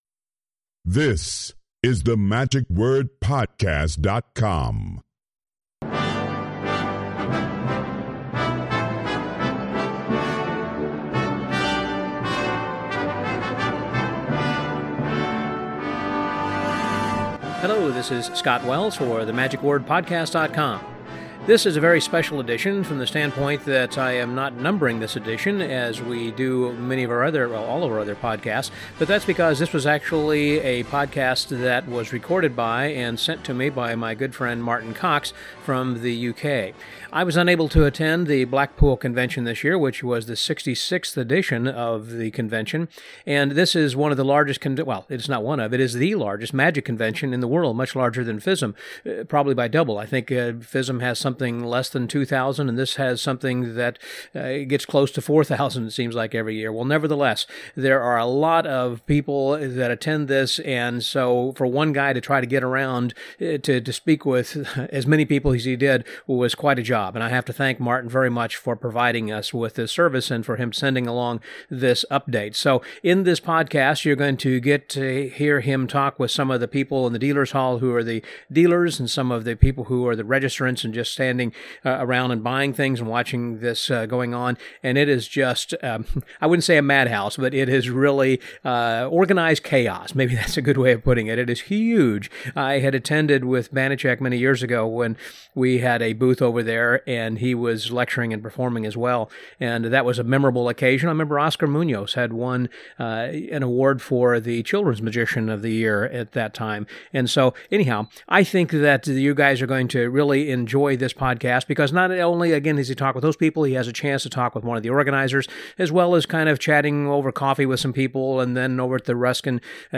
2018 Blackpool Report.mp3